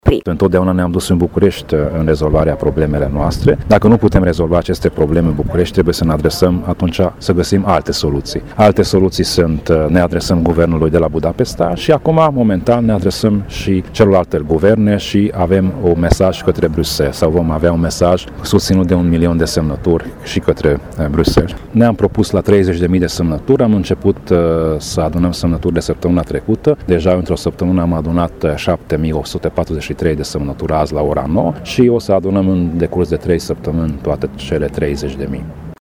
Preşedintele UDMR Covasna, Tamas Sandor, a declarat această iniţiativă este binevenită, deoarece comunitatea maghiară are o serie de doleanţe şi probleme specifice nerezolvate de multă vreme, cum ar fi înfiinţarea unei universităţi de stat cu predare în limba maternă, precum şi folosirea simbolurilor identitare proprii: